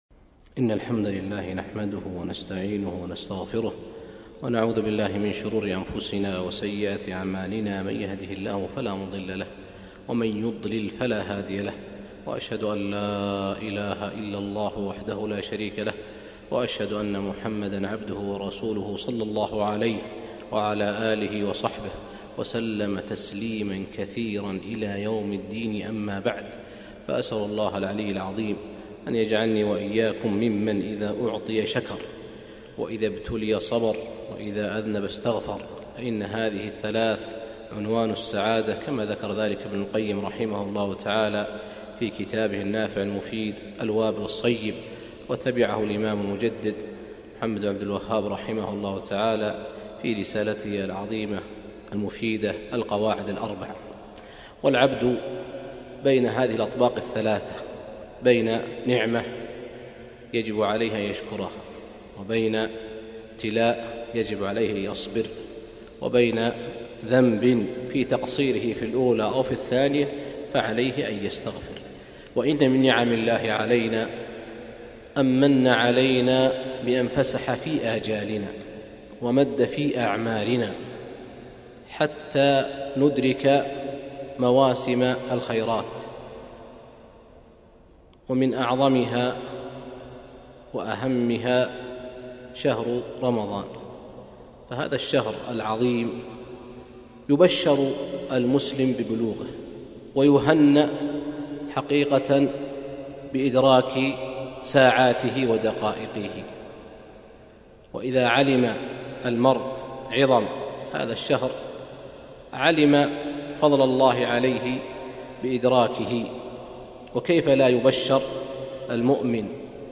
محاضرة - العبادة في رمضان